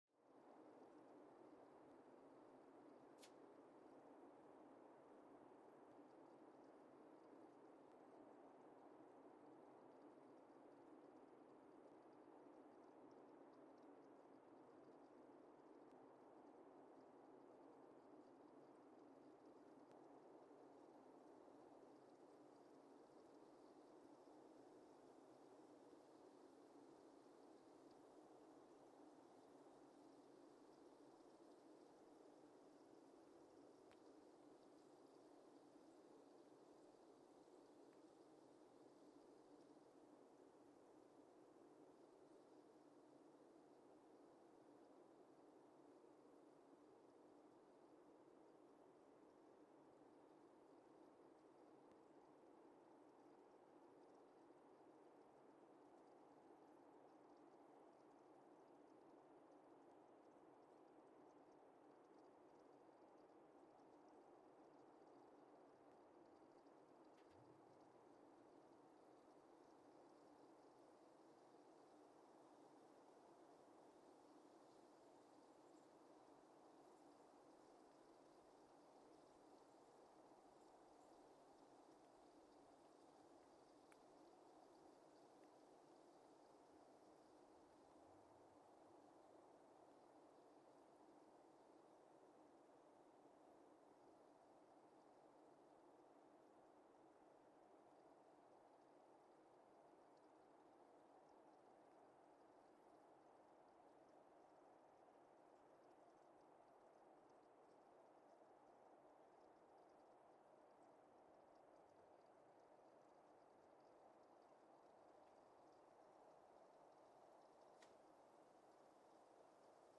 Weston, MA, USA (seismic) archived on December 8, 2024
Sensor : CMG-40T broadband seismometer
Speedup : ×1,800 (transposed up about 11 octaves)
Loop duration (audio) : 05:36 (stereo)
SoX post-processing : highpass -2 90 equalizer 300 2q -6 equalizer 400 2q -6 equalizer 90 12q 6